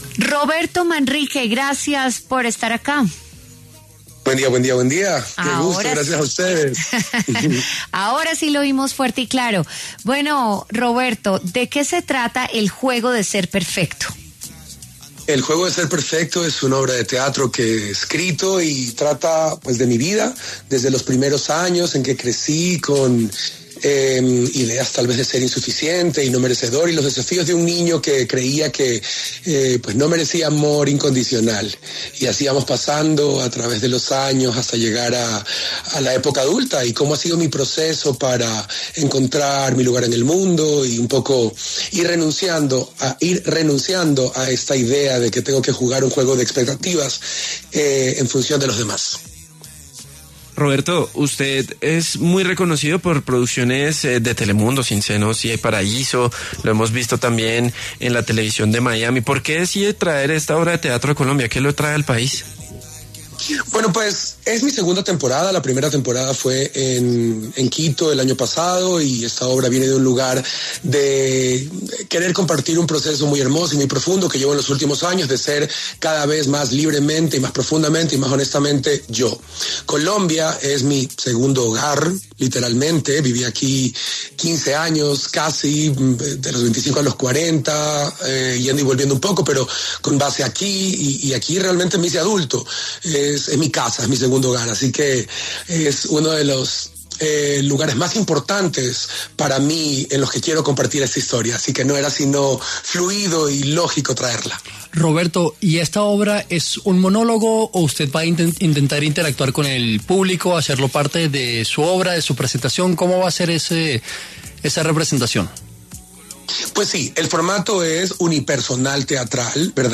Roberto Manrique, actor ecuatoriano, habló con W Fin De Semana a propósito de su obra de teatro ‘El juego de ser perfecto’, la cual llega a la Casa E Borrero y tendrá funciones de jueves a sábado del 6 de febrero al 15 de marzo.